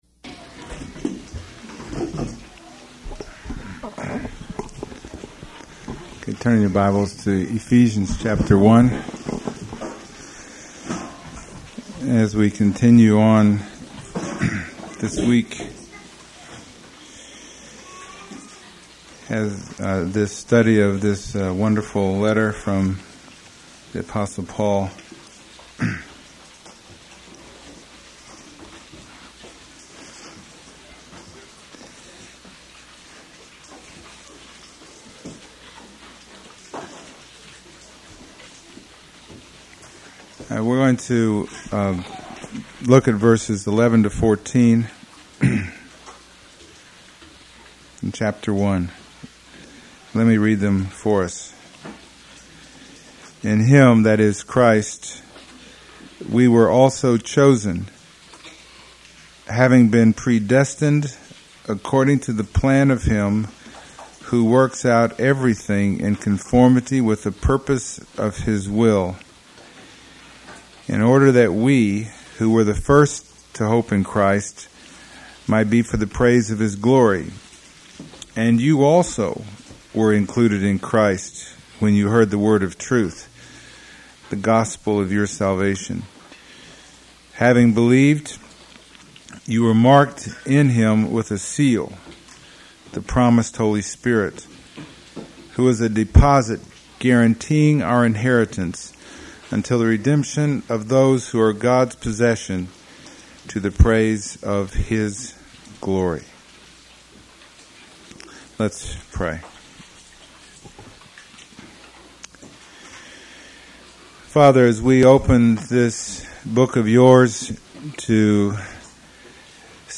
Ephesians 1:11-14 Service Type: Sunday Morning %todo_render% « Ephesians